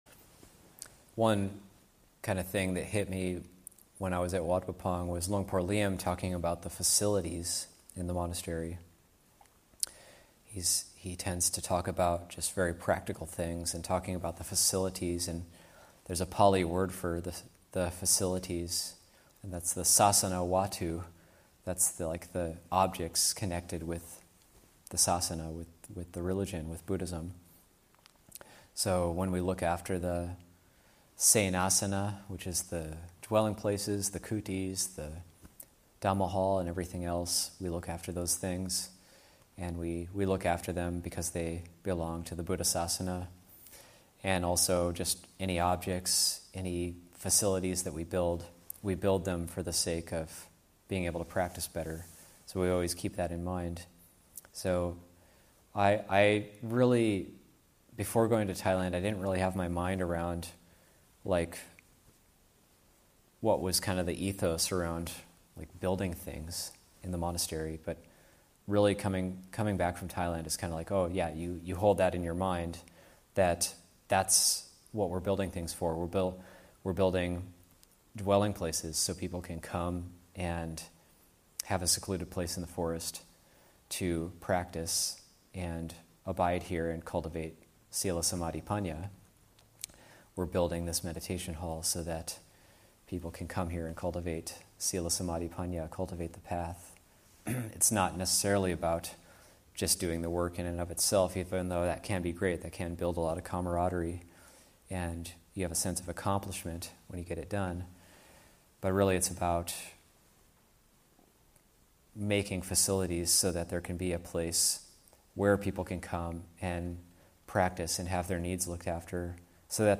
Abhayagiri 25th Anniversary Retreat, Session 11 – Jun. 12, 2021